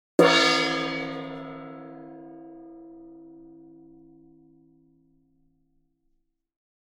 Download Free Gong Sound Effects | Gfx Sounds
Medium-sized-gong-hit.mp3